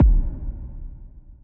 heartbeat.wav